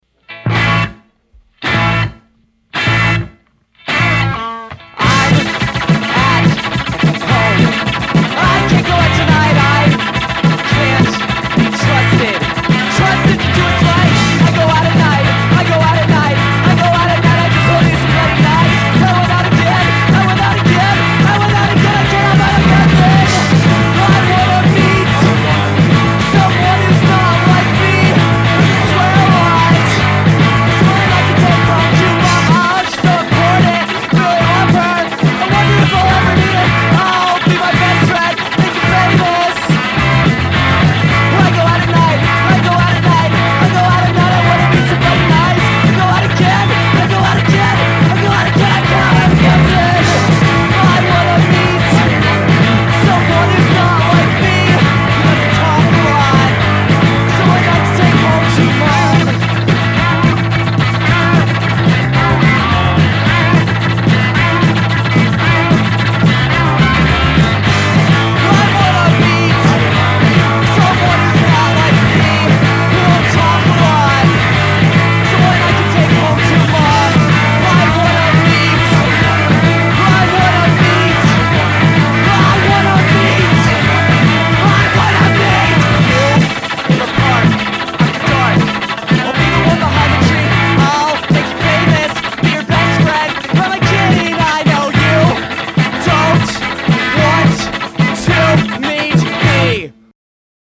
Outstanding panic from Minneapolis.